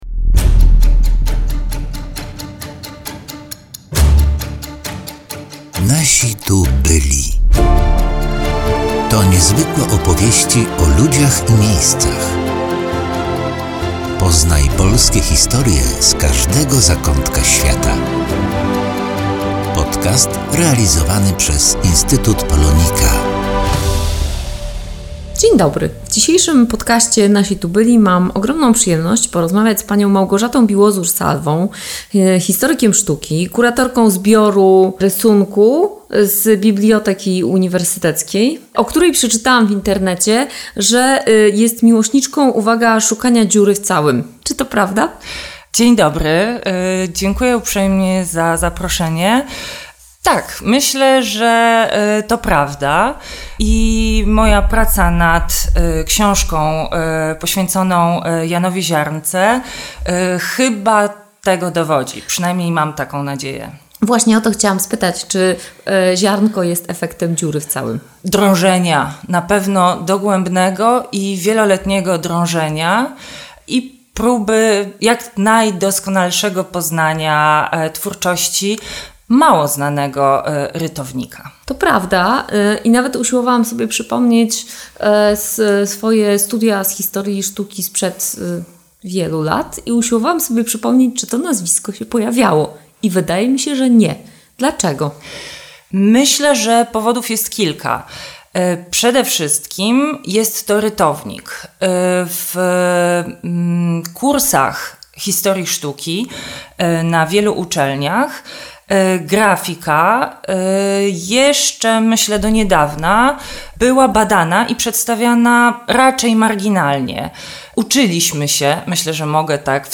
Rozmawiamy o warsztacie oraz twórczości urodzonego we Lwowie malarza i rytownika, przyglądamy się alegorycznym motywom i symbolom zawartym w jego pracach, a także dowiadujemy się, gdzie można zobaczyć ich oryginalne wersje.